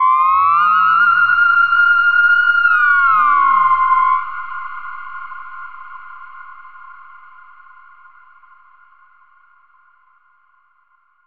Theremin_Swoop_04.wav